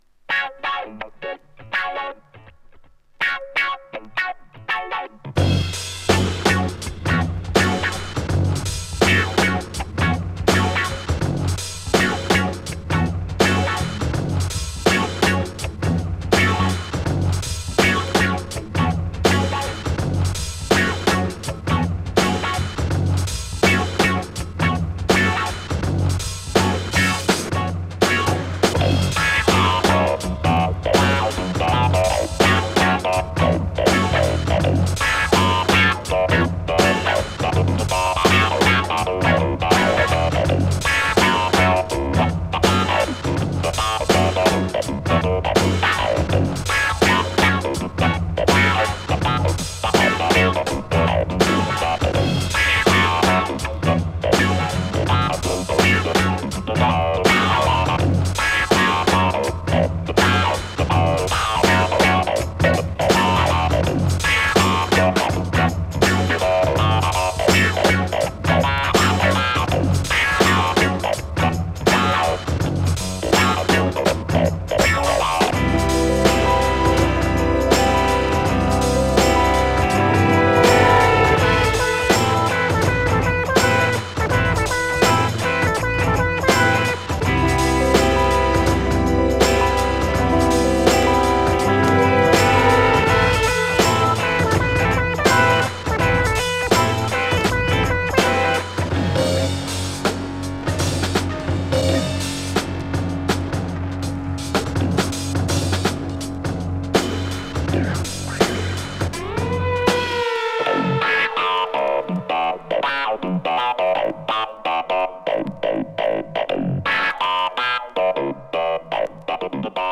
3. > JAZZ FUNK/RARE GROOVE
ファンク・ブレイクビーツラインのサイケデリック・スペースファンクアルバム !